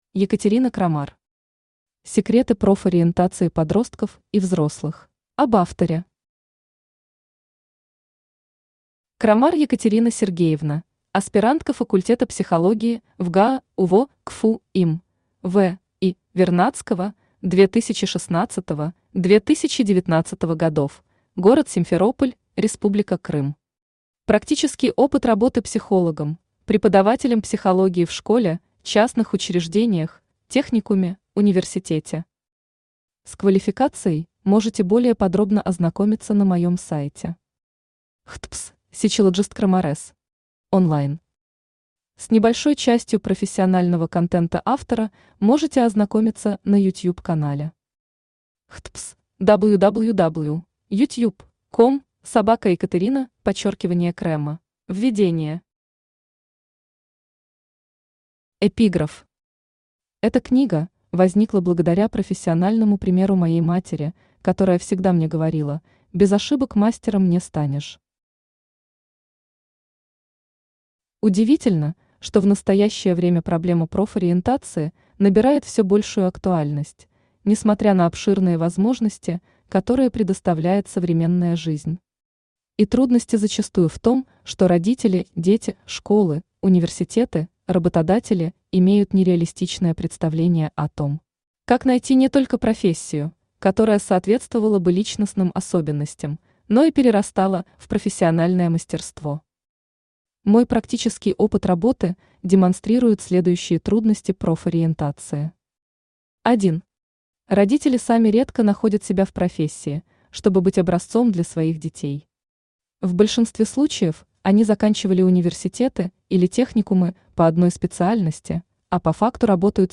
Aудиокнига Секреты профориентации подростков и взрослых Автор Екатерина Сергеевна Крамар Читает аудиокнигу Авточтец ЛитРес.